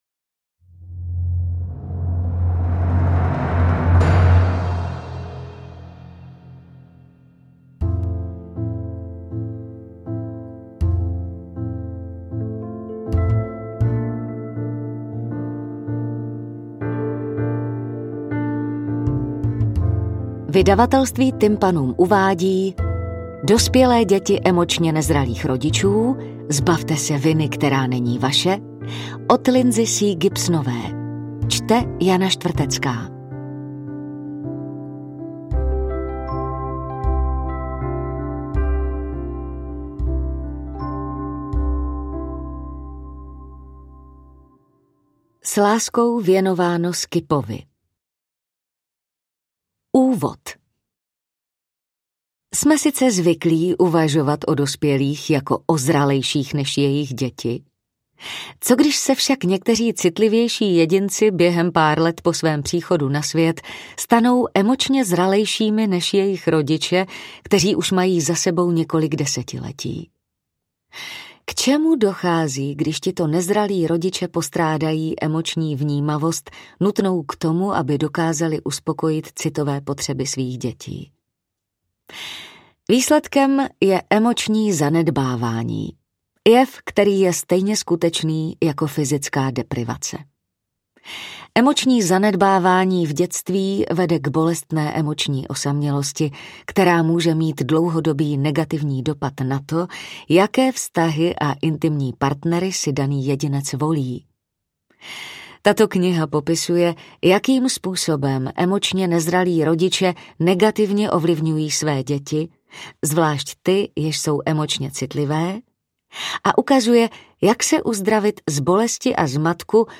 AudioKniha ke stažení, 23 x mp3, délka 8 hod. 24 min., velikost 461,0 MB, česky